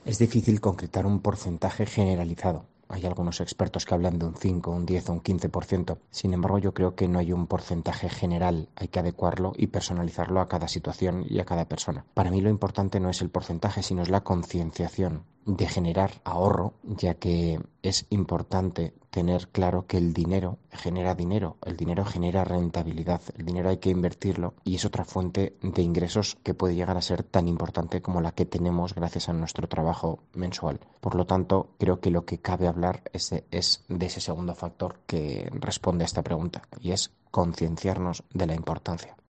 experto financiero, nos explica cuánto hay que ahorrar para tener buena jubilación